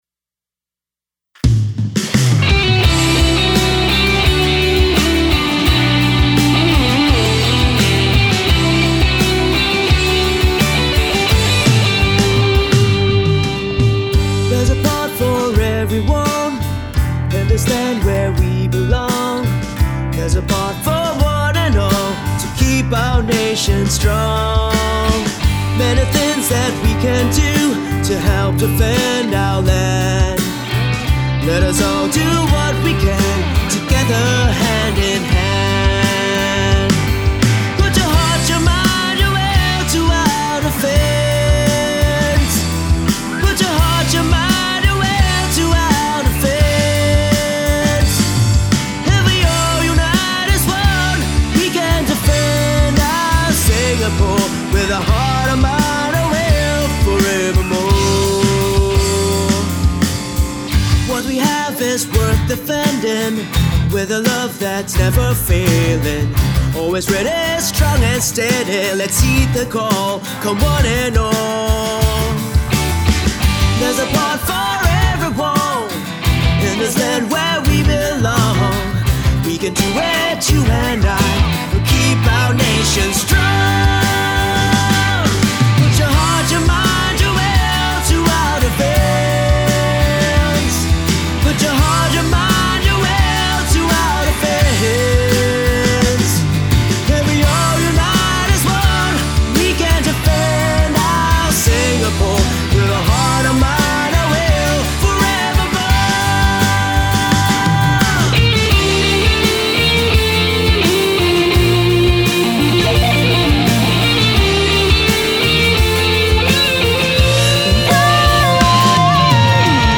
while retaining that familiar, nostalgic tune.